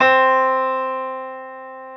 Keys (6).wav